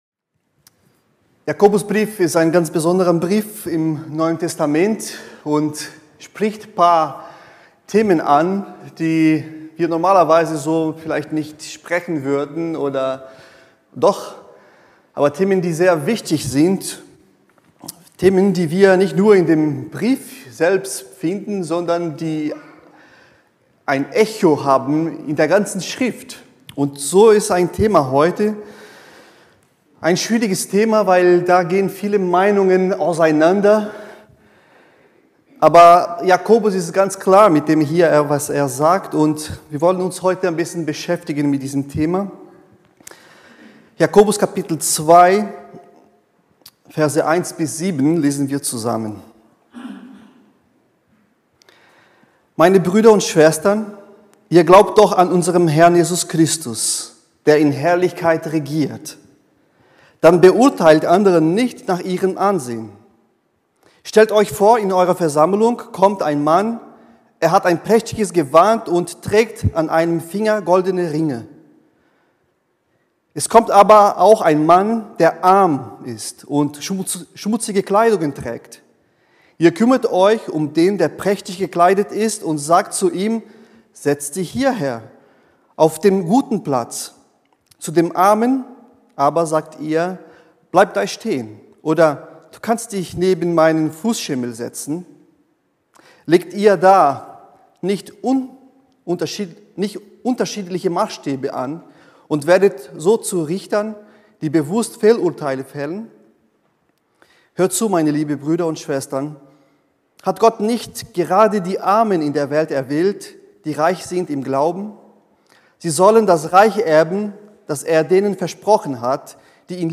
Predigten über den christlichen Glauben und Leben